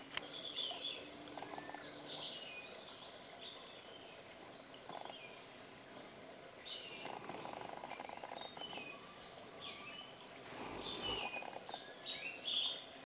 Zorzalito Overo (Catharus maculatus)
Nombre en inglés: Speckled Nightingale-Thrush
Localidad o área protegida: Parque Nacional Calilegua
Condición: Silvestre
Certeza: Vocalización Grabada